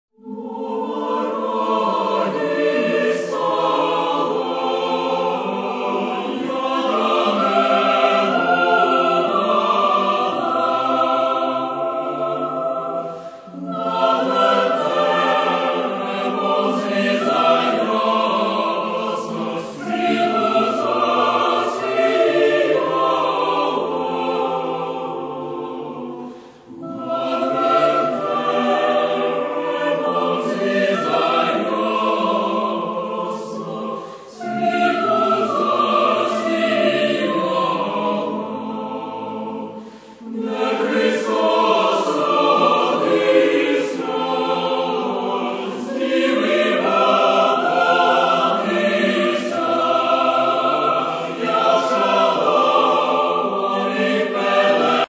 Christmas  (94)